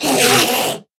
Minecraft Version Minecraft Version 1.21.5 Latest Release | Latest Snapshot 1.21.5 / assets / minecraft / sounds / mob / endermen / scream2.ogg Compare With Compare With Latest Release | Latest Snapshot
scream2.ogg